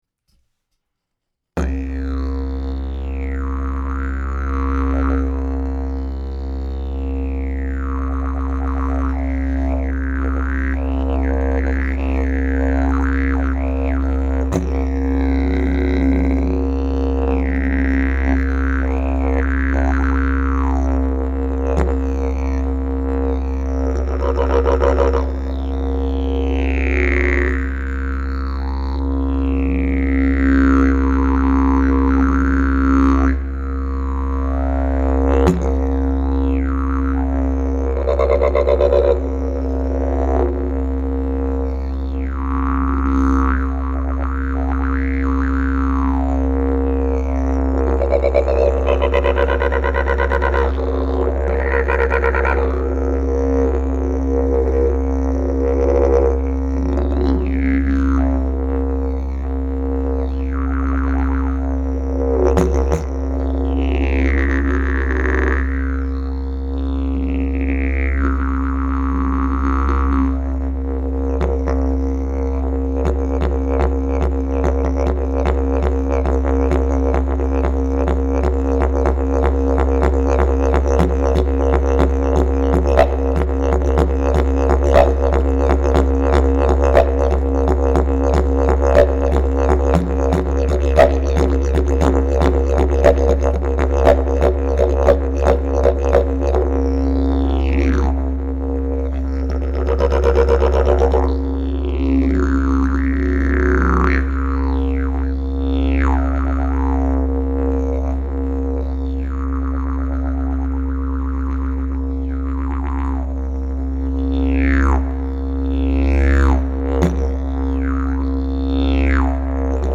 Key: C# Length: 65.5" Bell: 7.25" Mouthpiece: Red Zebrawood, Sapele, Bloodwood Back pressure: Very strong Weight: 4.4 lbs Skill level: Any
Didgeridoo #627 Key: C/C#